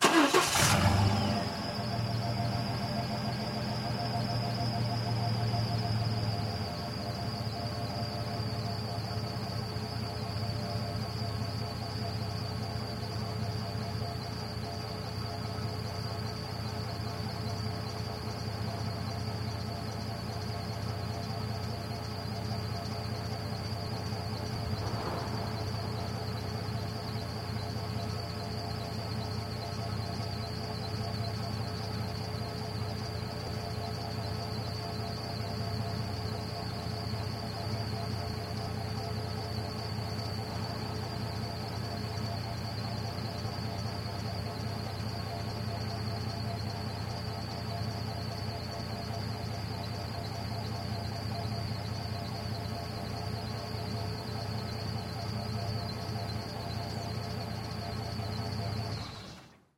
Настройка звука выхлопной системы автомобиля БМВ